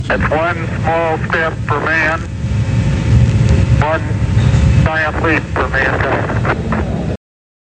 Al pisar la Luna, Neil dijo una frase que ha pasado a la posteridad: